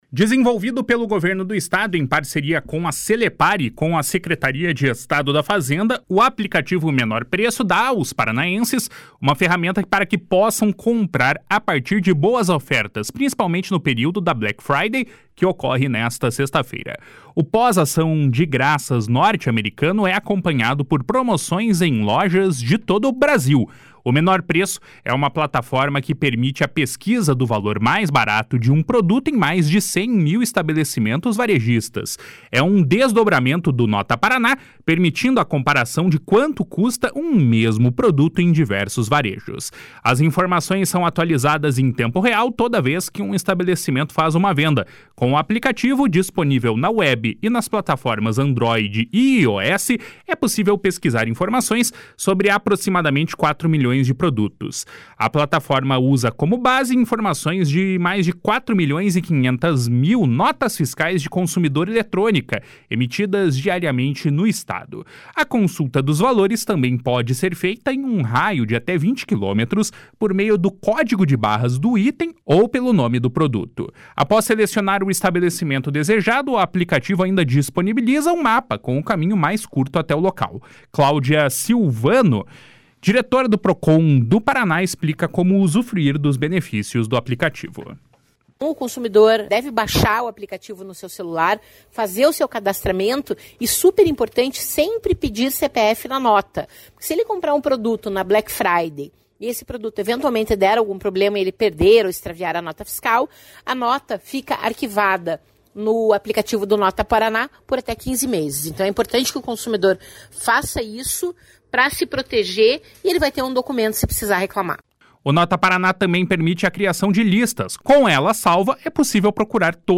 Claudia Silvano, diretora do Procon Paraná, explica como usufruir dos benefícios do aplicativo.  // SONORA CLAUDIA SILVANO //